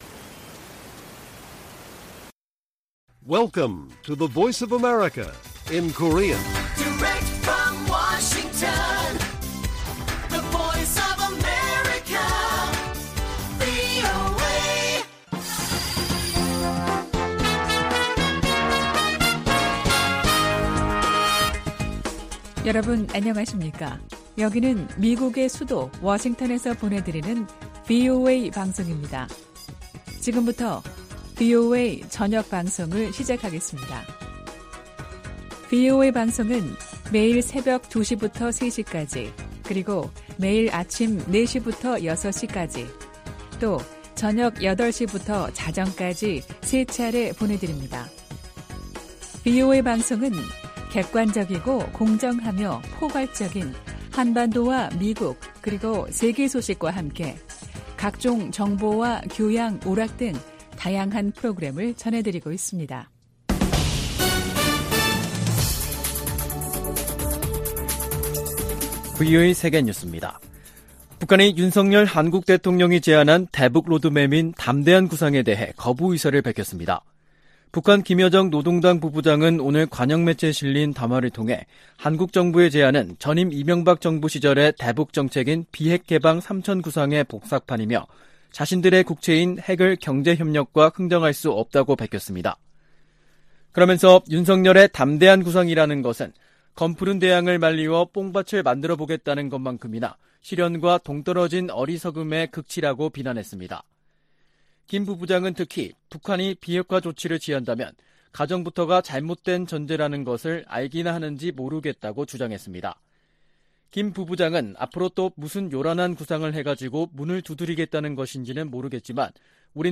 VOA 한국어 간판 뉴스 프로그램 '뉴스 투데이', 2022년 8월 19일 1부 방송입니다. 김여정 북한 노동당 부부장이 윤석열 한국 대통령의 '담대한 구상'을 정면 거부하는 담화를 냈습니다. 미 국무부는 북한이 대화 의지를 보이면 비핵화를 위한 점진적 단계가 시작되지만 이를 거부하고 있어 실질적 단계에 도입하지 못하고 있다고 밝혔습니다. 미국의 전문가들은 윤 한국 대통령의 한일 관계 개선 의지 표명을 긍정적으로 평가했습니다.